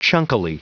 Prononciation du mot chunkily en anglais (fichier audio)